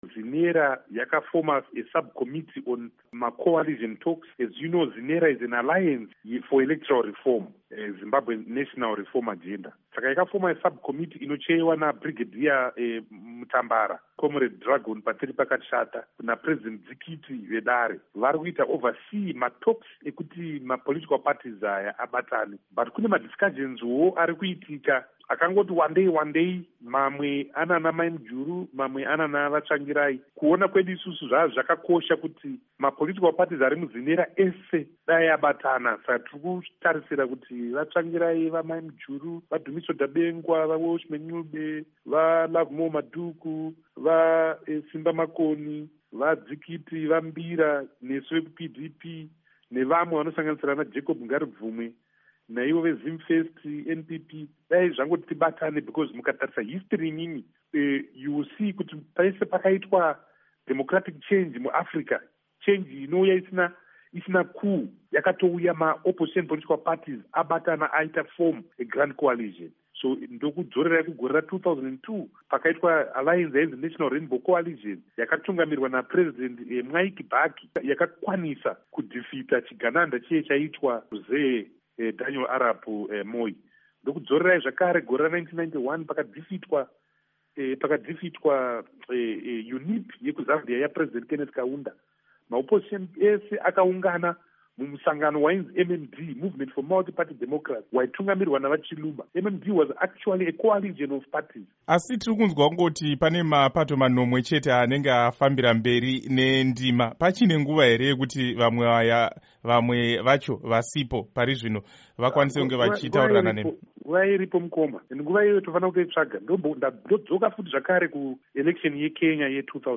Hurukuro naVaTendai Biti